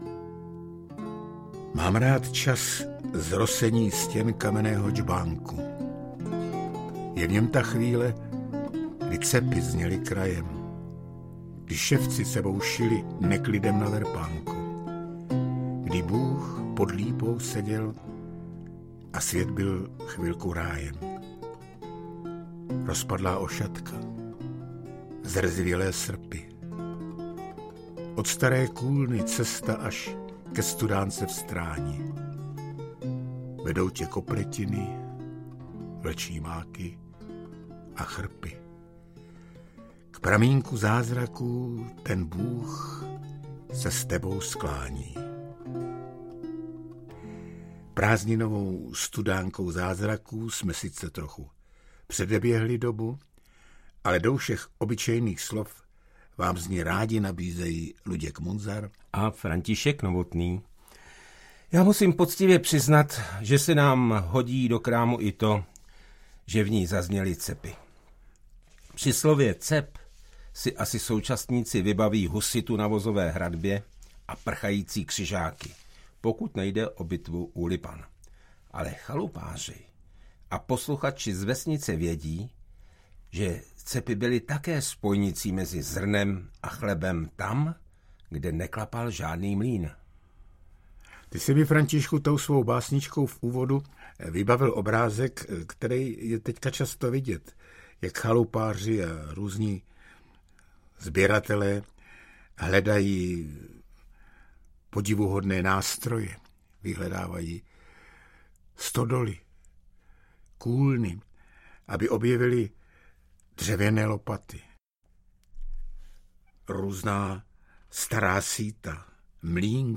Okouzlení slovem audiokniha
Audiokniha Okouzlení slovem - výběr z úspěšného rozhlasového cyklu z roku 2015. Účinkují František Novotný a Luděk Munzar